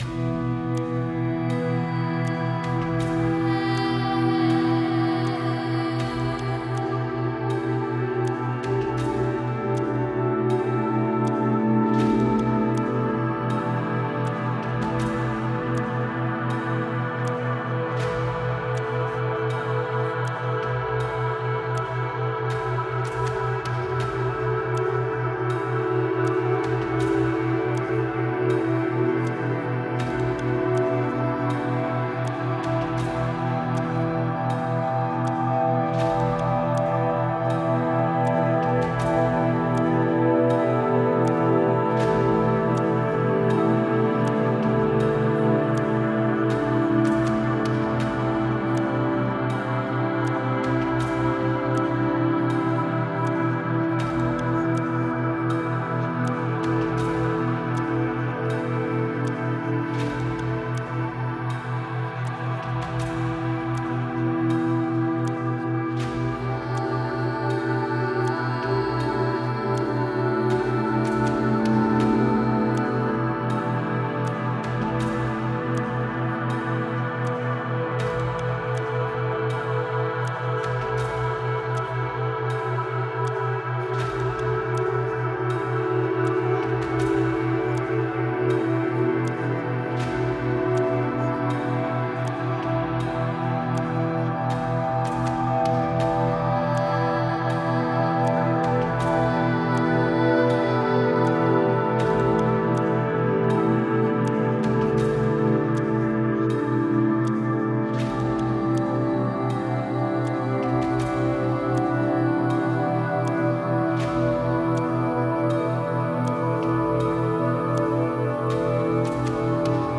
(Version RELAXANTE)
Pures ondes thêta apaisantes 4Hz de qualité supérieure.